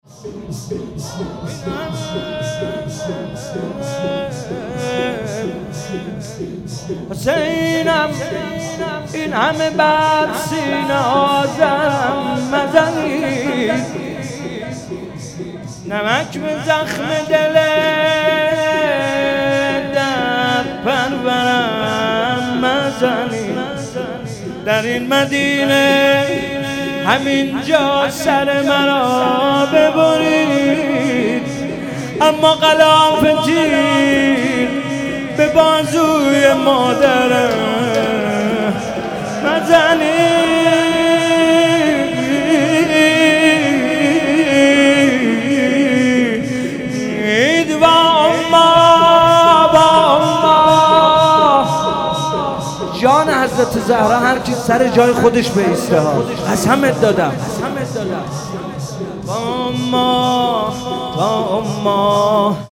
مداحی
در ایام فاطمیه اول و در روز شهادت حضرت زهرا (س) را می‌شنوید.
مراسم سوگواری و عزاداری حضرت زهرا (س) در ایام فاطمیه اول